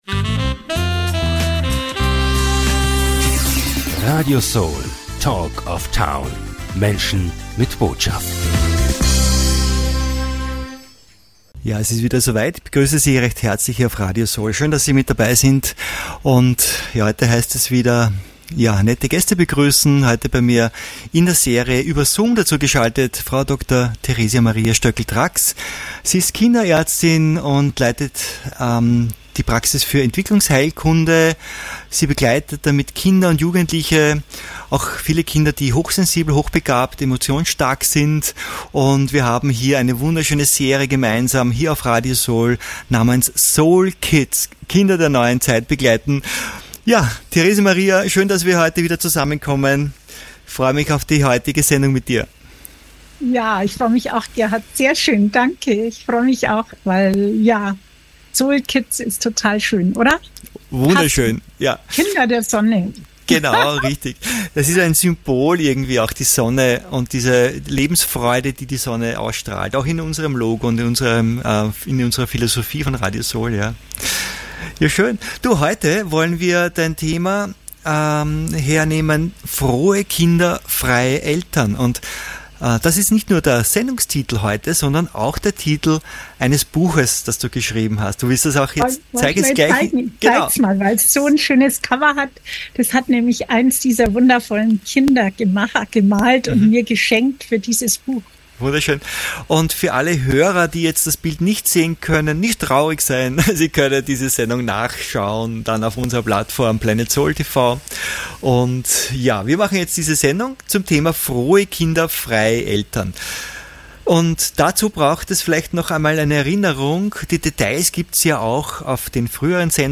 Ein Interview für alle, denen die nächsten Generationen, die Kinder und Enkel am Herzen liegen.